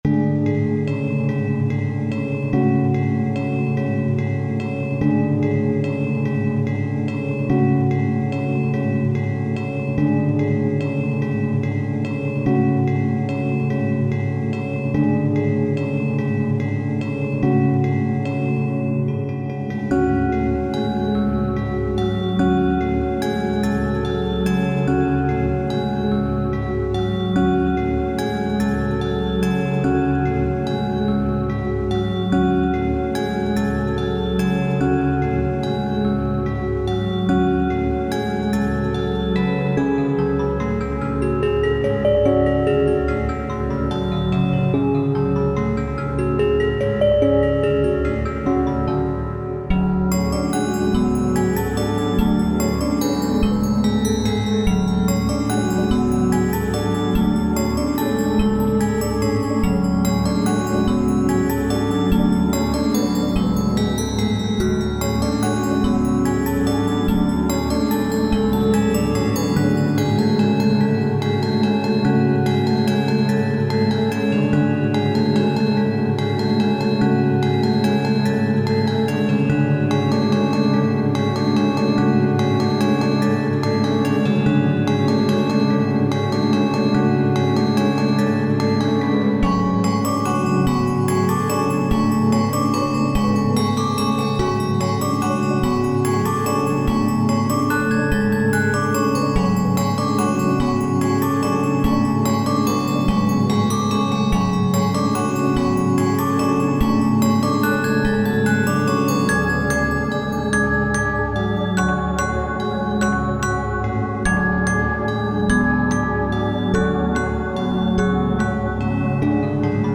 ホラー/怖い 不思議/ミステリアス 不気味/奇妙 和風 寂しい/悲しい 幻想的 怪しい 暗い 虚無/退廃 コメント